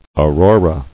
[Au·ro·ra]